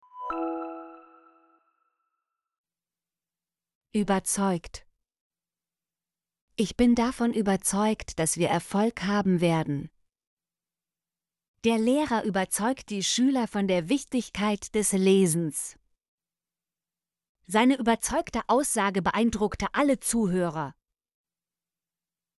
überzeugt - Example Sentences & Pronunciation, German Frequency List